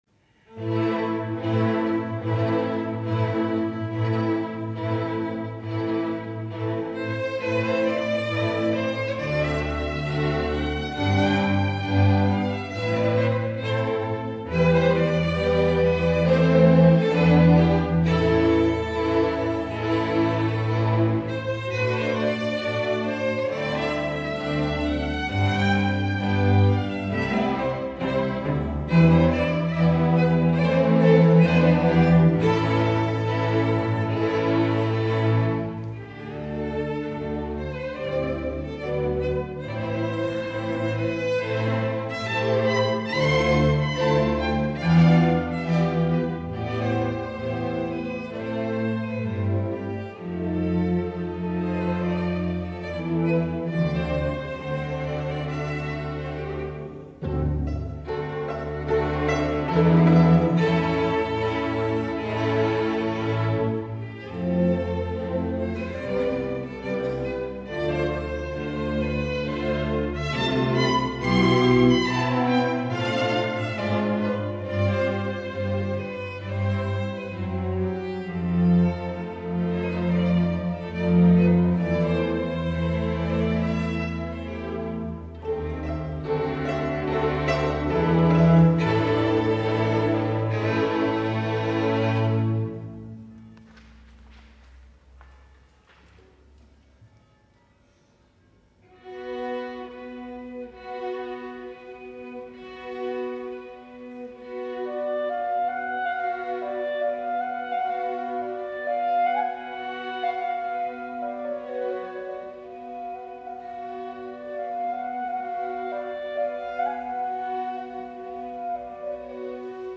Dal concerto spettacolo